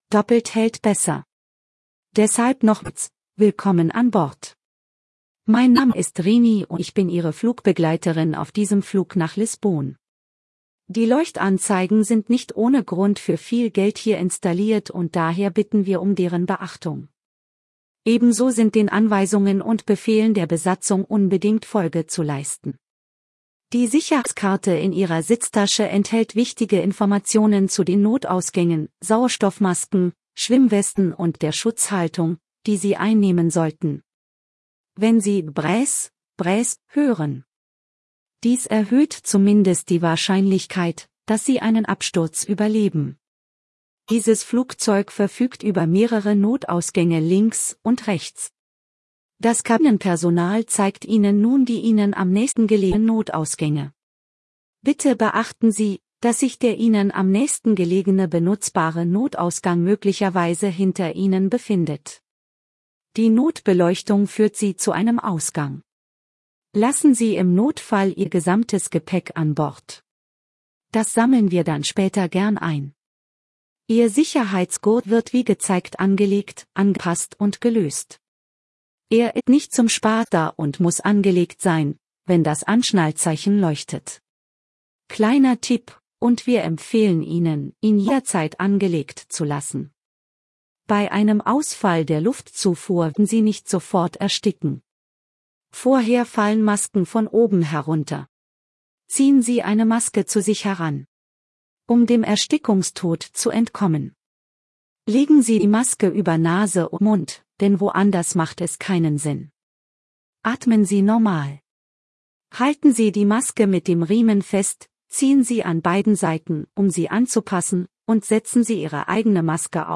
SafetyBriefing.ogg